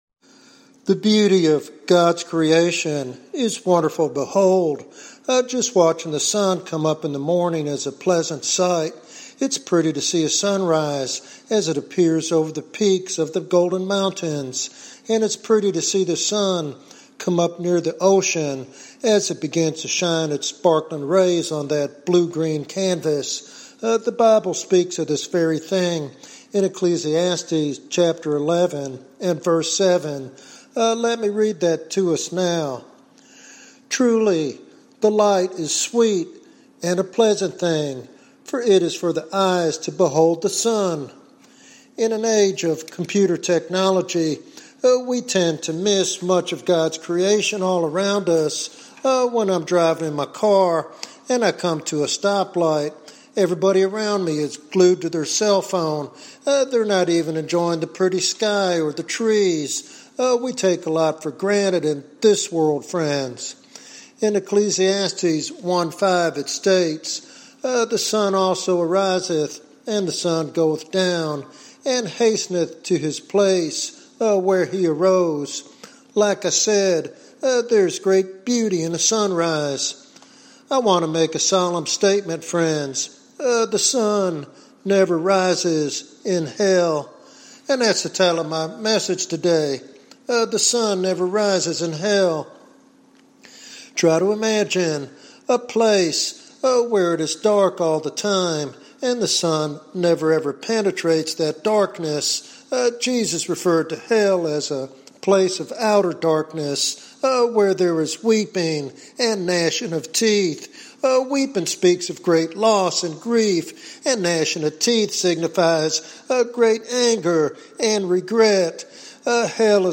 This sermon challenges believers and seekers alike to consider their eternal destiny and the hope found only in Jesus.